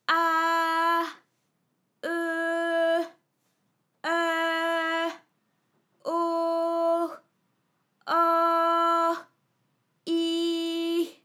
ALYS-DB-001-FRA - First, previously private, UTAU French vocal library of ALYS
-ahh-ehh-euhh-ohh-auhh-ihh.wav